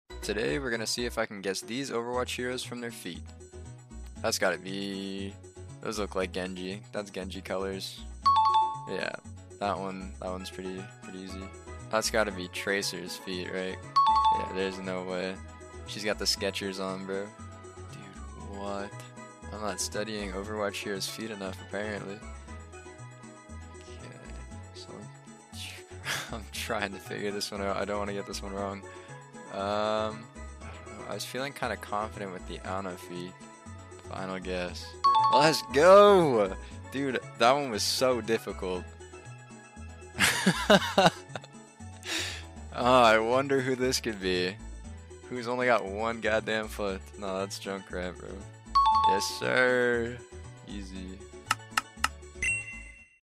Guessing Overwatch Heroes From Their Sound Effects Free Download